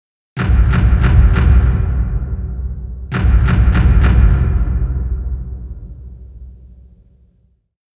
creepyhits1.wav